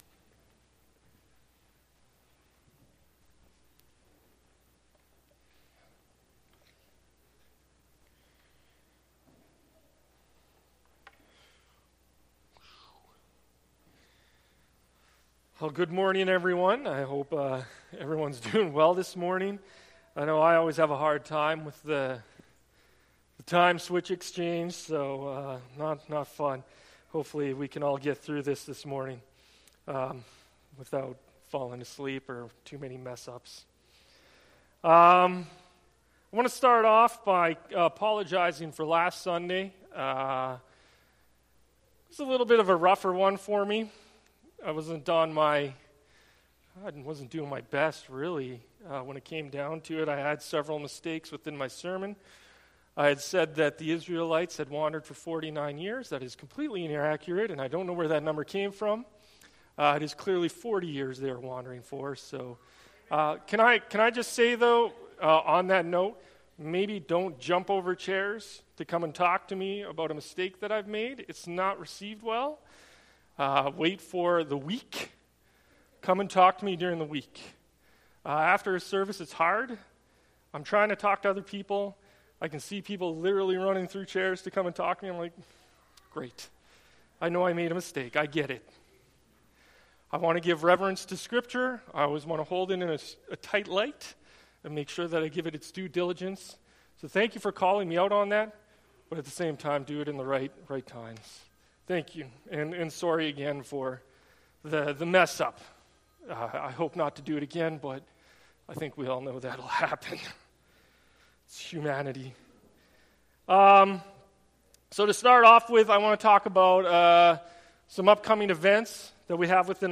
Sermons | Muir Lake Community Alliance Church